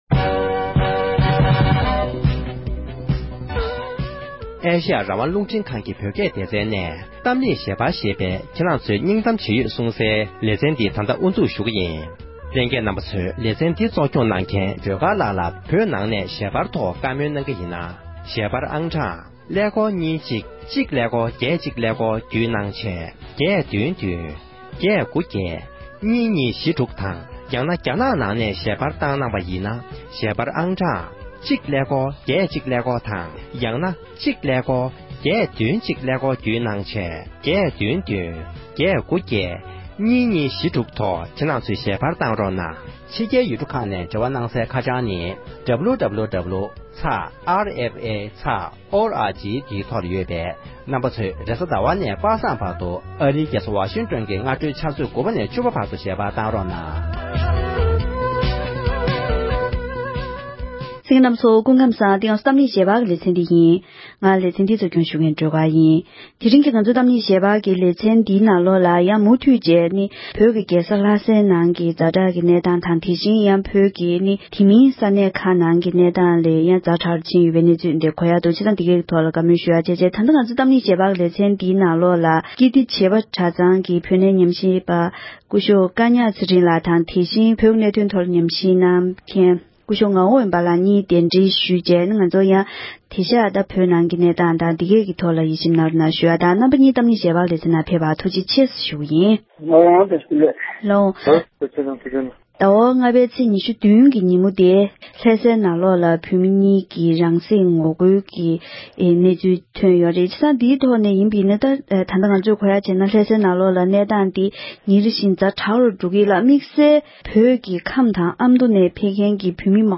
འབྲེལ་ཡོད་མི་སྣ་དང་བཀའ་མོལ་ཞུས་པར་གསན་རོགས་ཞུ༎